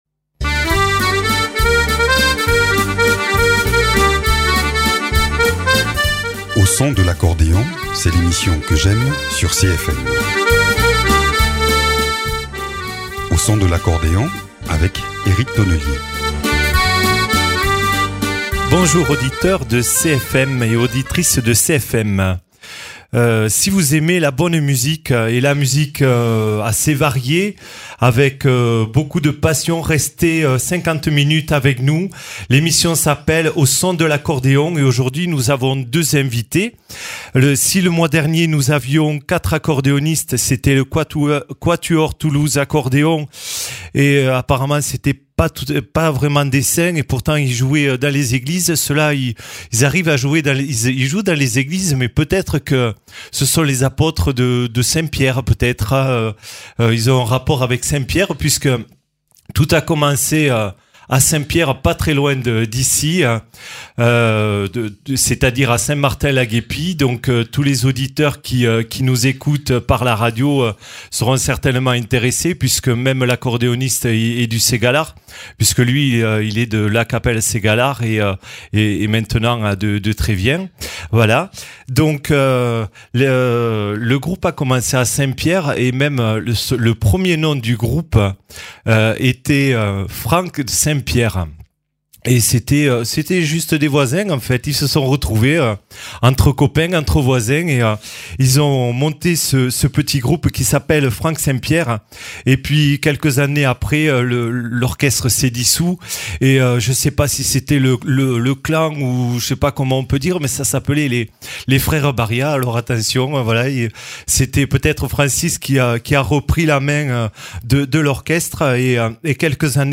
Sur scène, piano à queue, 2 accordéons, guitare, batterie, 3 chanteurs offrent au public tous les standards de la musique de danse : Musette, Variétés, Folklore.